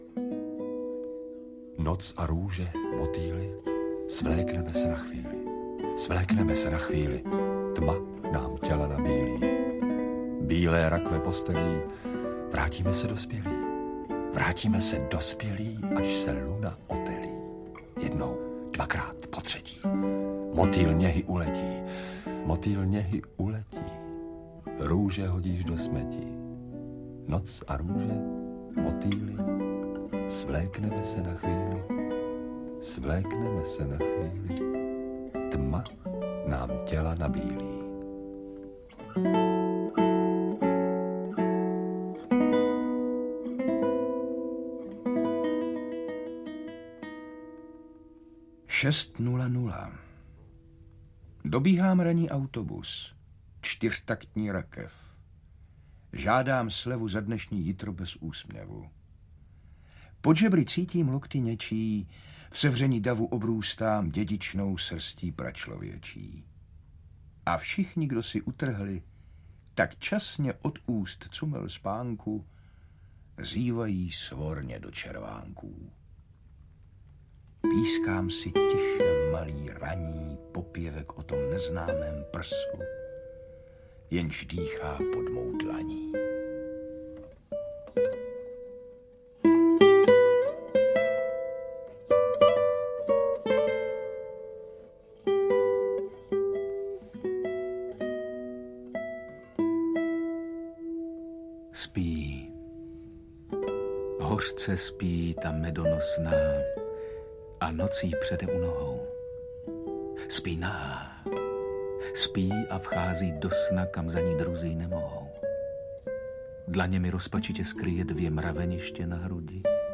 Lyrická sezóna, Okurková sezóna audiokniha
Audiokniha obsahuje verše pro dospělé, některé humorné, jiné jako zamyšlení nad životem, aforismy a parodie. Získaly si své čtenáře a v interpetaci skvělých herců Petra Pelzera, Borise Rösnera a Viktora Preisse i své posluchače.
• InterpretPetr Pelzer, Boris Rösner, Viktor Preiss